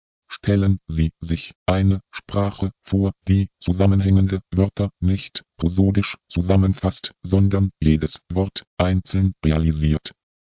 Die abgehackte, unzusammenhängende Rede zeigt deutlich, dass die Strukturierung der Wörter im Satz interessieren sollte.